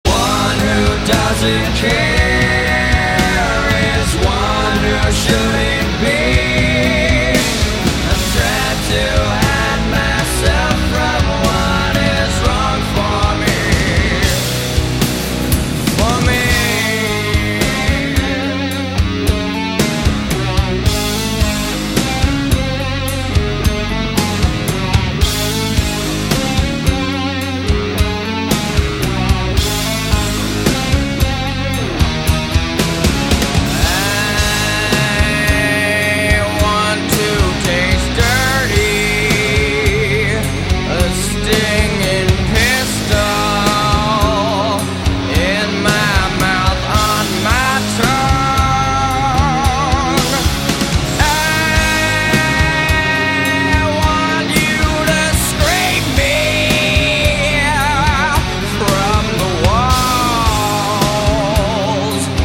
- Un classic du mouvement Grunge